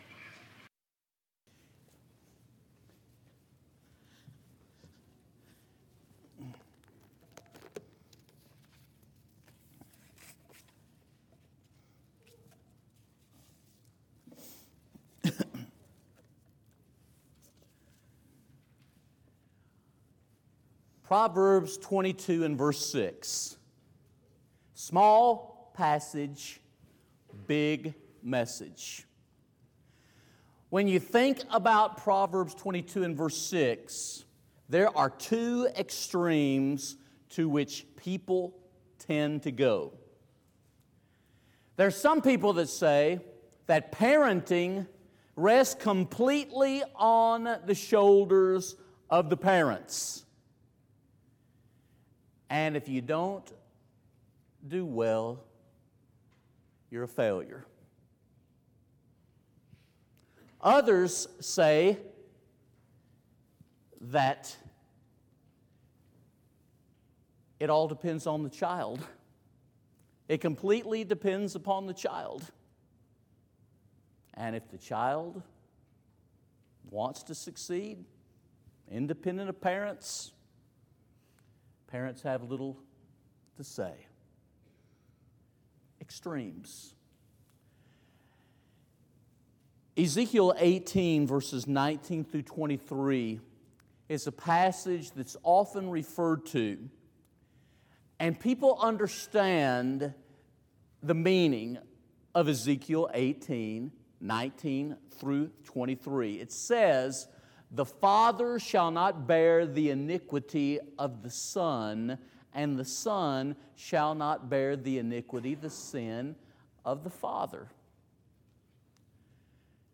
Sermons by Westside Church of Christ